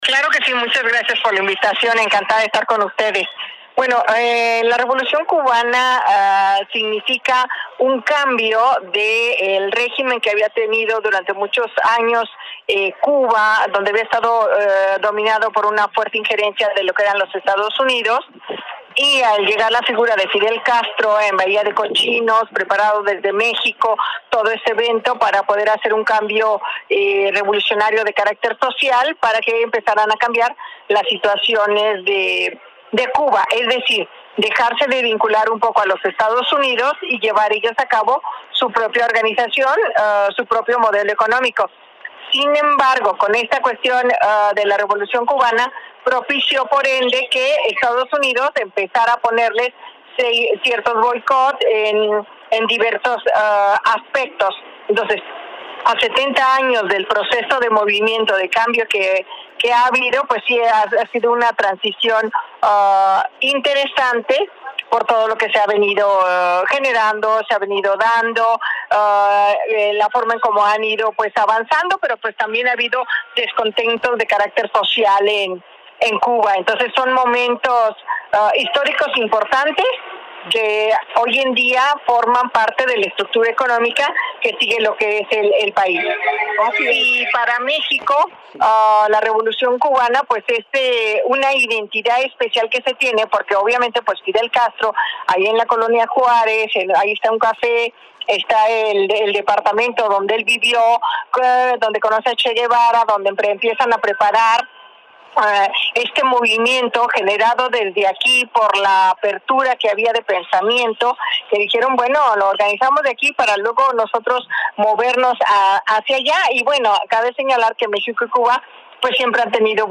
22-ENTREVISTA.mp3